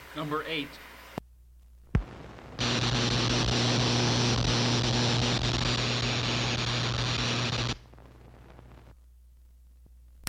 复古电气声音 " G3205雅各布的梯子
描述：雅各布的梯子 高压电弧。
随着电力上升而改变音高的嗡嗡声以及随着电量的增加而发出一些咔 这些是20世纪30年代和20世纪30年代原始硝酸盐光学好莱坞声音效果的高质量副本。 40年代，在20世纪70年代早期转移到全轨磁带。我已将它们数字化以便保存，但它们尚未恢复并且有一些噪音。
标签： 电弧 经典 电力
声道立体声